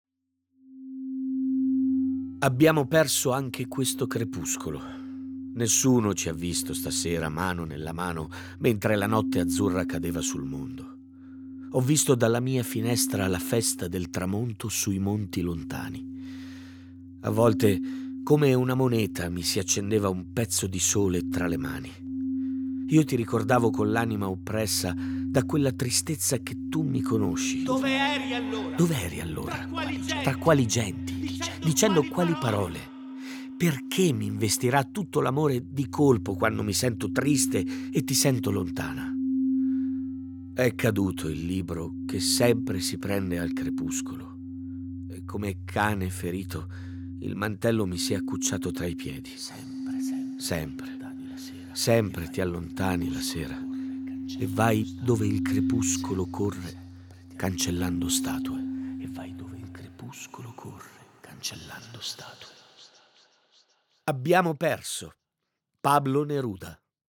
Abbiamo immaginato un contenitore dove si possano ascoltare delle prime letture poetiche.